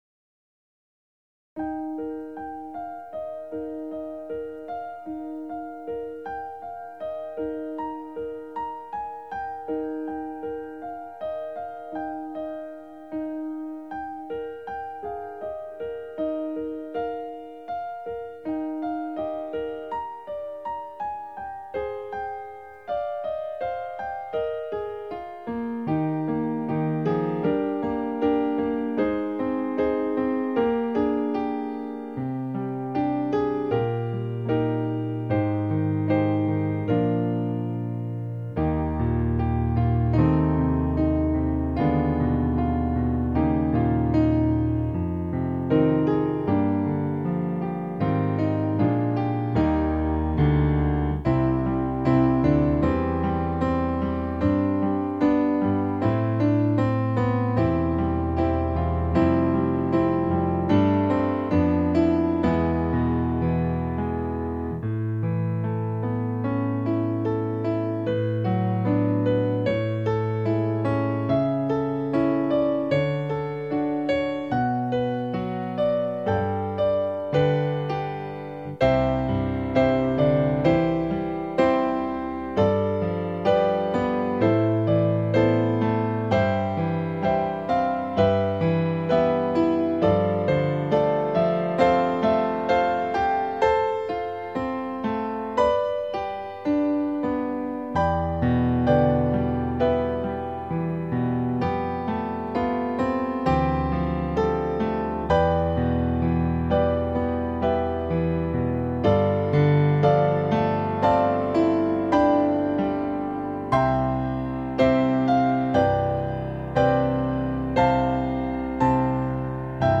Voicing/Instrumentation: Piano Prelude/Postlude , Piano Solo